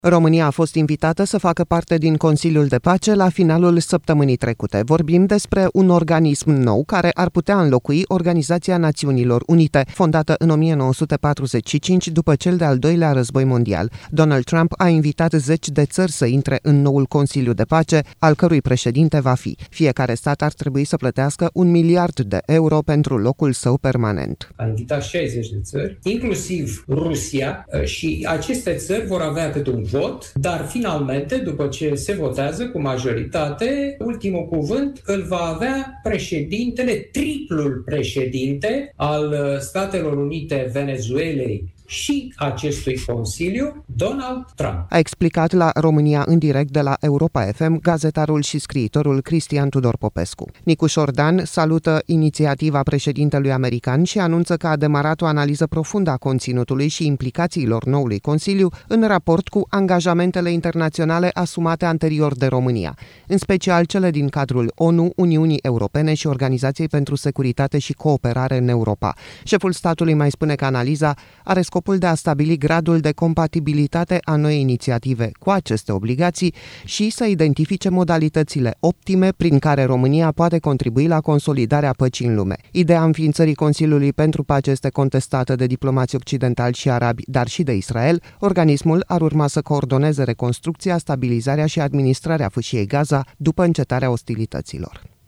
„A invitat 60 de țări, inclusiv Rusia, iar aceste țări vor avea câte un vot, dar, finalmente, după ce se votează cu majoritate, ultimul cuvânt îl va avea președintele, triplul președinte, al Statelor Unite, Venezuelei și al acestui Consiliu, Donald Trump”, a explicat, la „România în direct”, gazetarul și scriitorul Cristian Tudor Popescu.